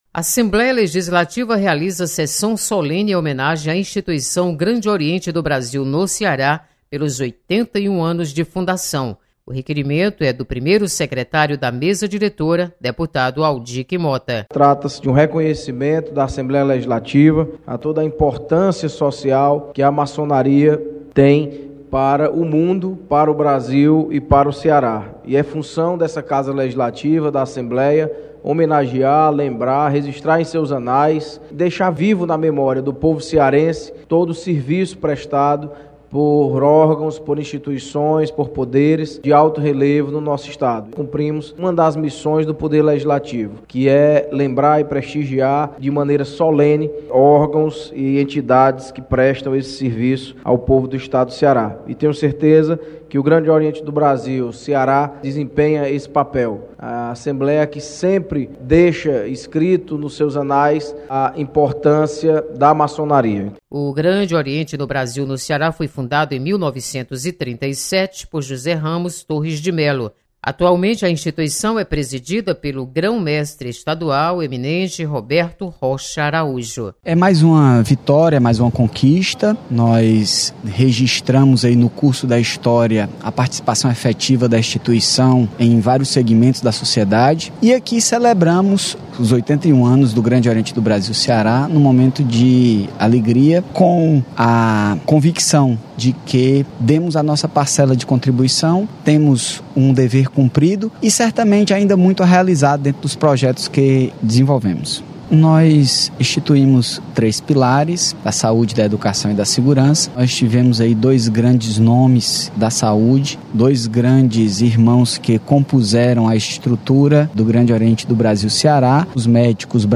Grande Oriente do Brasil recebe homenagem da Assembleia Legislativa. Repórter